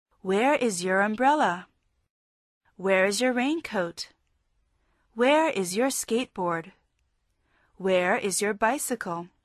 Este curso OM TALK de conversación fue desarrollado en inglés americano.